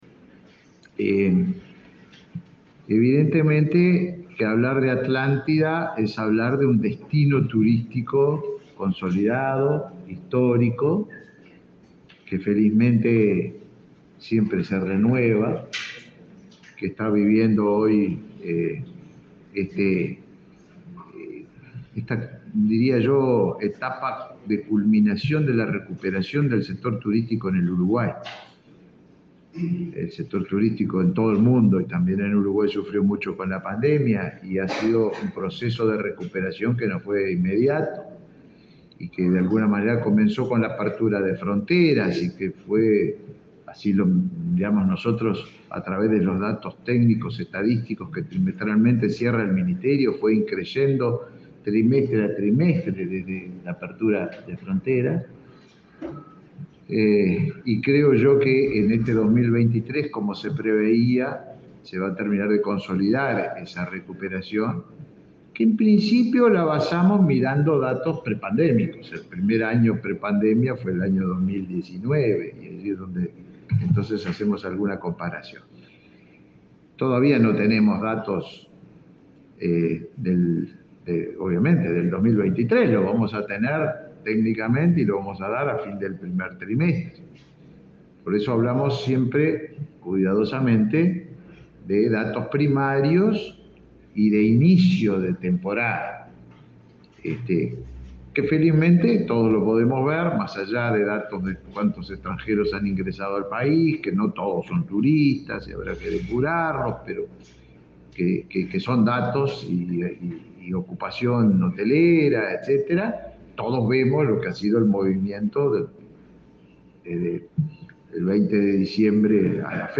Palabras del ministro de Turismo, Tabaré Viera
El ministro de Turismo, Tabaré Viera, participó en Montevideo del lanzamiento del Atlántida Rock Festival.